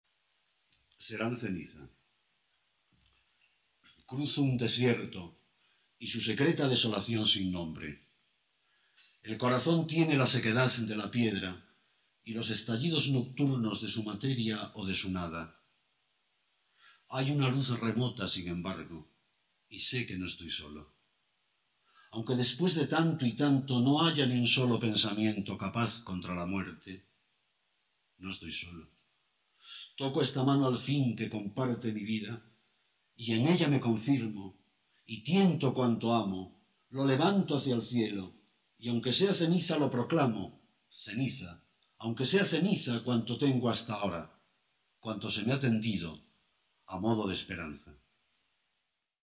Autor del audio: El propio autor del poema